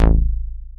DOWN BASS A2.wav